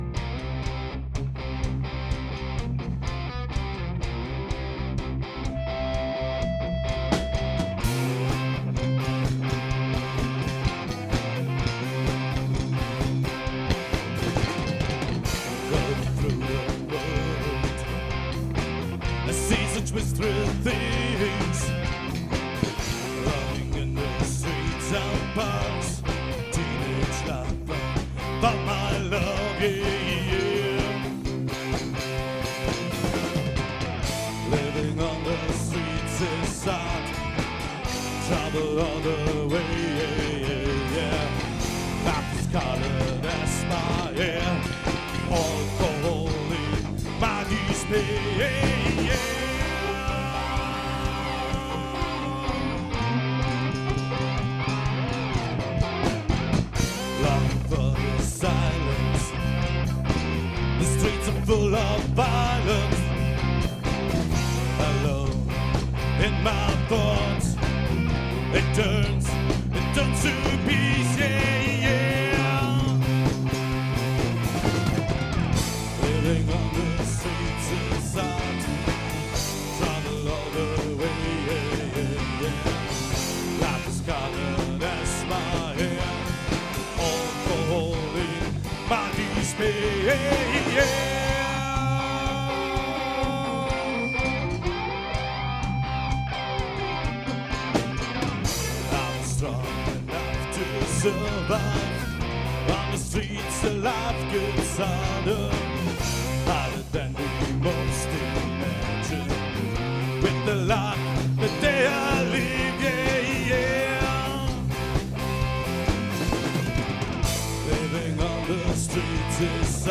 01-Streetlight-Live.mp3